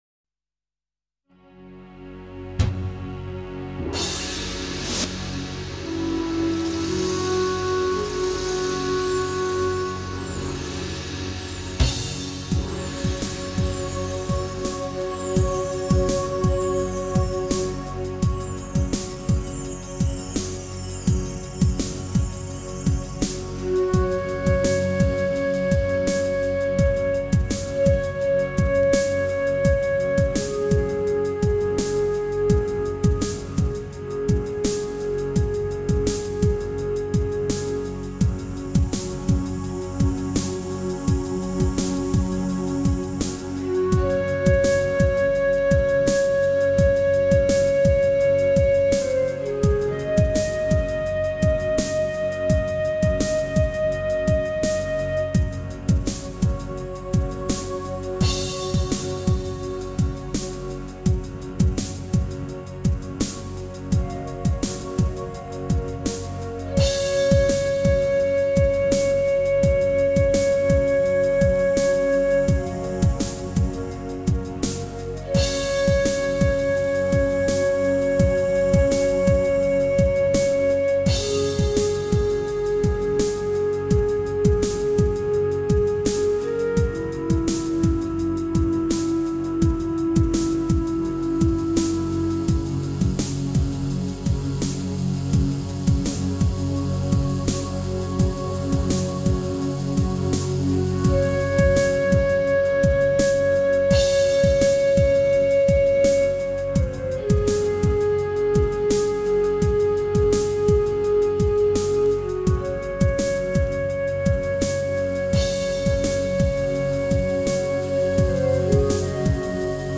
其间充满了梦幻的草香和微痛的渴望 整张专辑呈现出既清新又神秘的奇妙感受 相当耐人寻味。
以一种神秘而新奇的曲风刻画探险心情，乐声由远而近，由幽长而急促，逐渐将整个情绪带进去诡异氛围